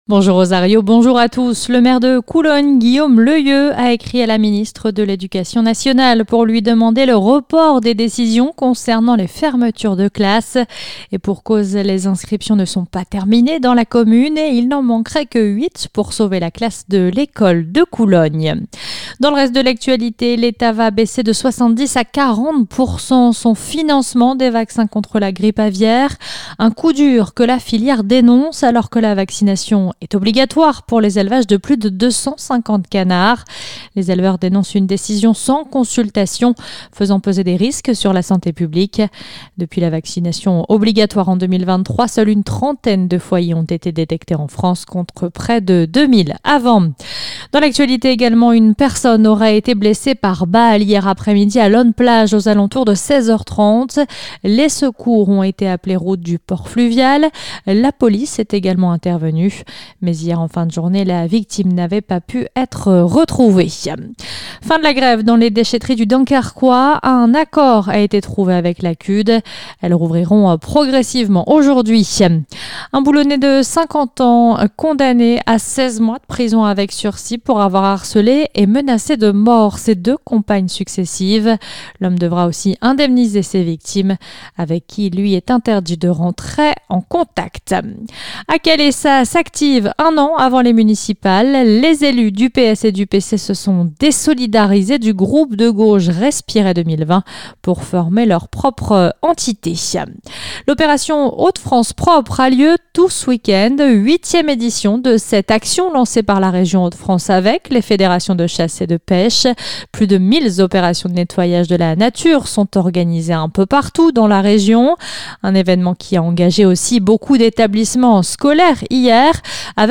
Le journal du samedi 15 mars 2025